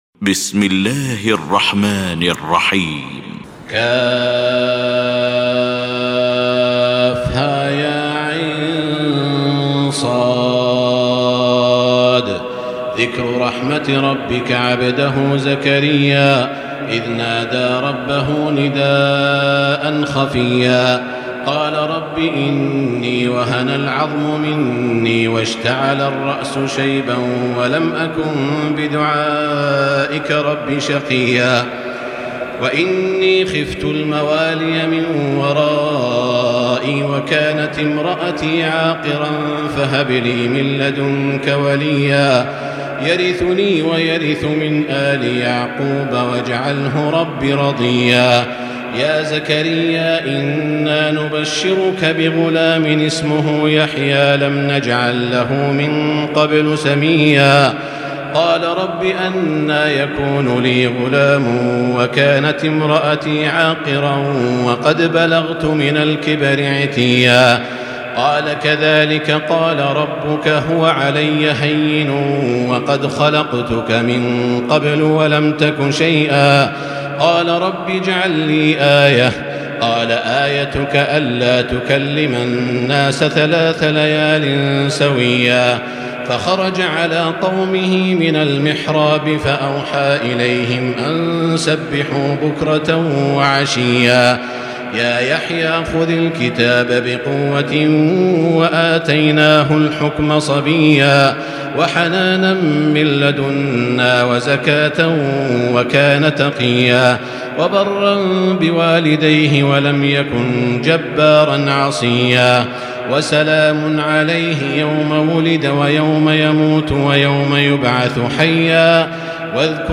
المكان: المسجد الحرام الشيخ: معالي الشيخ أ.د. بندر بليلة معالي الشيخ أ.د. بندر بليلة سعود الشريم مريم